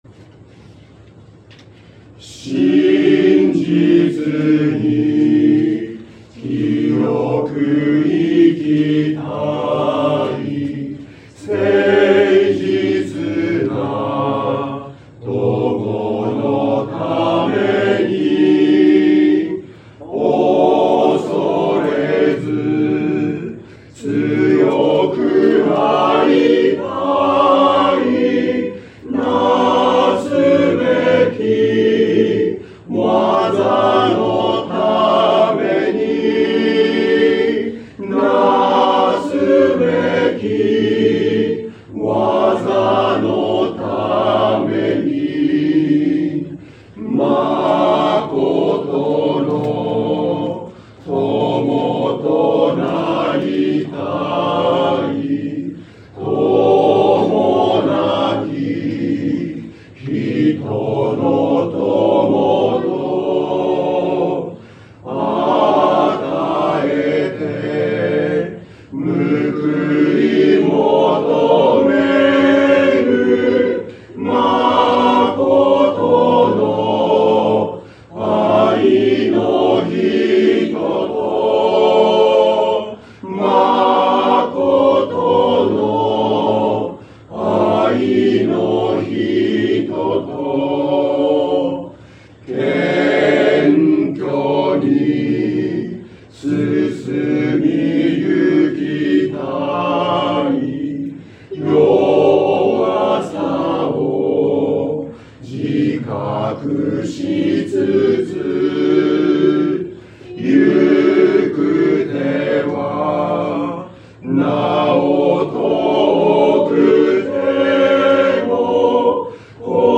聖歌隊による賛美の歌声
礼拝での奉仕の様子